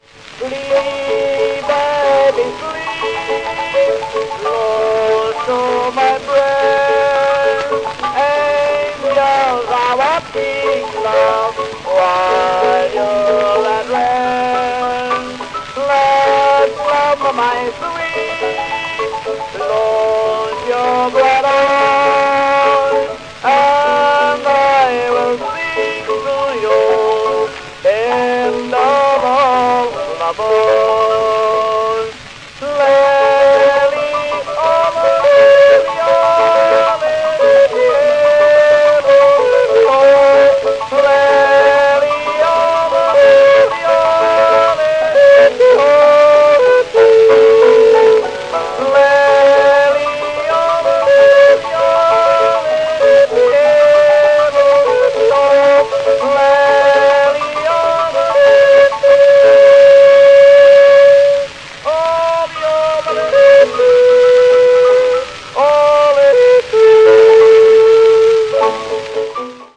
Um die Jahrhundertwende ging George P. Watson mit den ersten Jodel-Plattenaufnahmen überhaupt in die US-amerikanische Geschichte ein. Die aus Europa stammende Sangeskunst hatte sich im Vaudeville bereits Mitte des 19. Jahrhunderts etabliert und hielt später auch im Countrybereich Einzug.